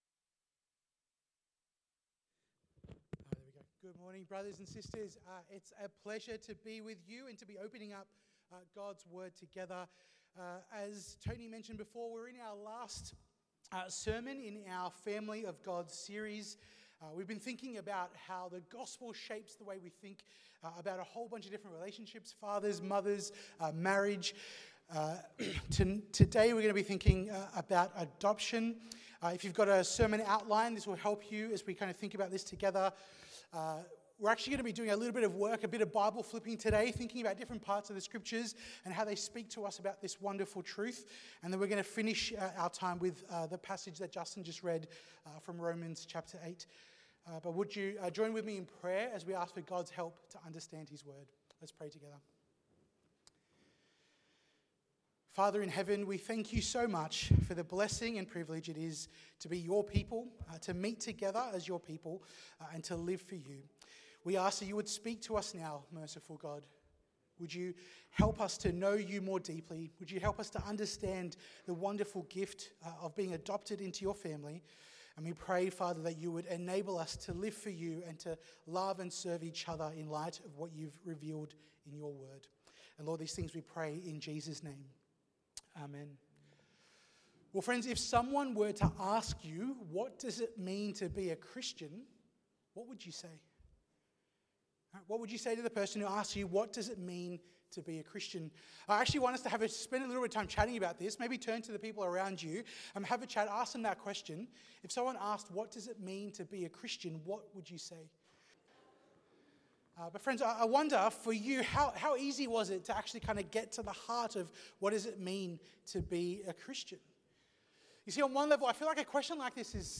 Watch the full service on YouTube or listen to the sermon audio only.